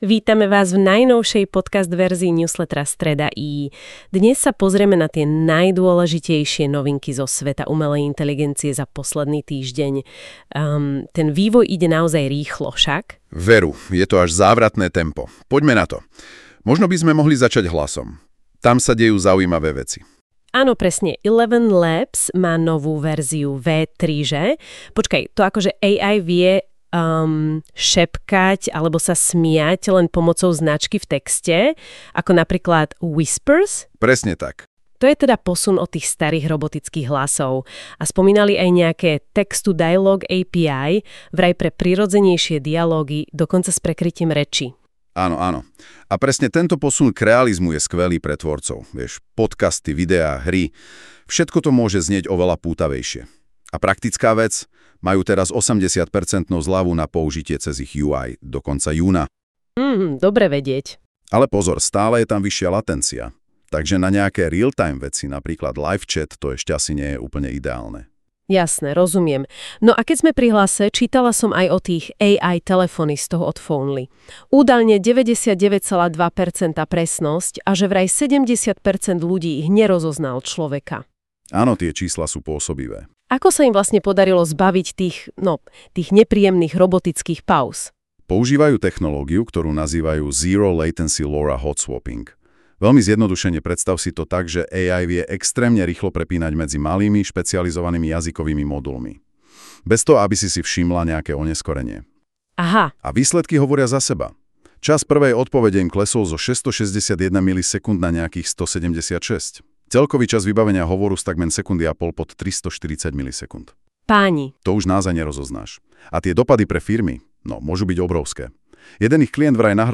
🚀 Prichádzajú najnovšie trendy v AI s týždenným sumárom od STREDAi. Pripravte sa na tieto novinky v AI generovanej podcast verzii: